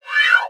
VEC3 Scratching FX